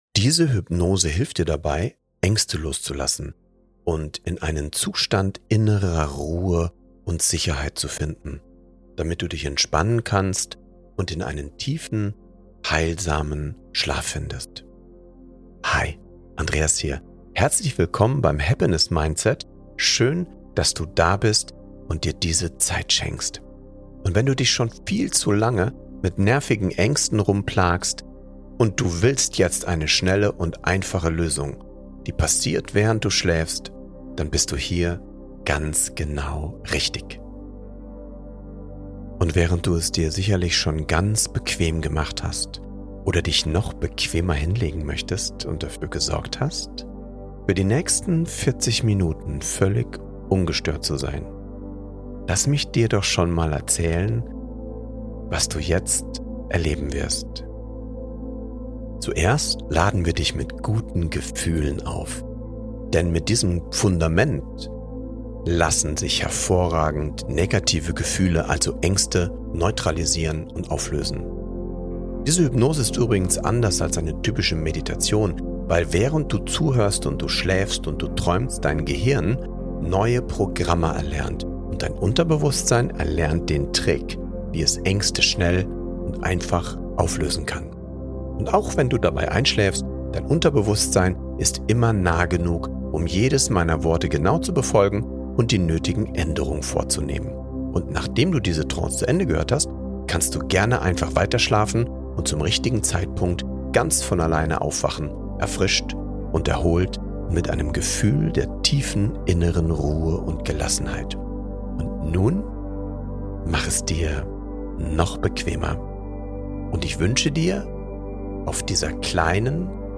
Diese geführte Hypnose hilft dir, innere Blockaden und Ängste loszulassen – sanft, effektiv und nachhaltig. Entwickelt, um dich emotional zu entlasten, dein Nervensystem zu beruhigen und dich sicher und geborgen in den Schlaf zu begleiten.